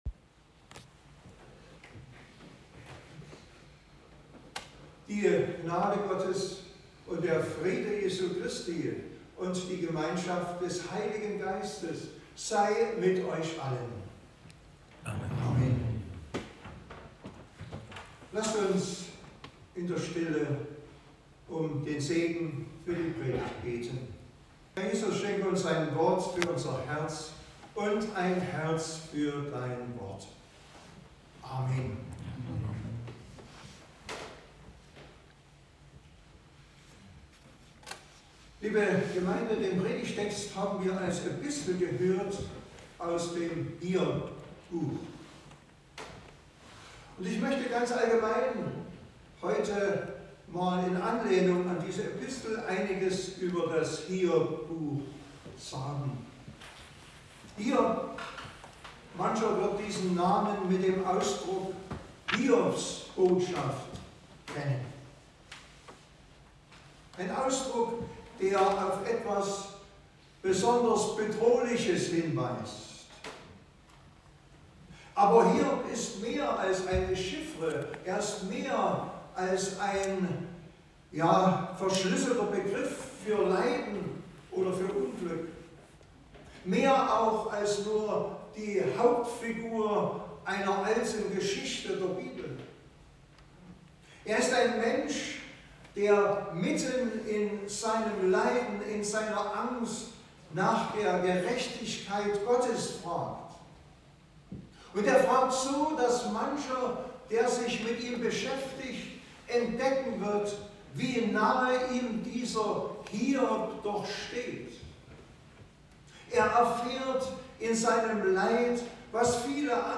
1-6 Gottesdienstart: Predigtgottesdienst Wildenau Hiob hat Gott vertraut